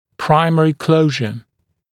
[‘praɪmərɪ ‘kləuʒə][‘праймэри ‘клоужэ]первичное закрытие (напр. о расщелине нёба)